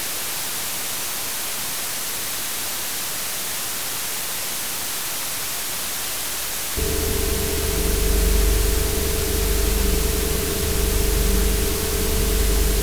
Вот запись эквивалента и микрофона:
ЭквивалентИмикрофон.wav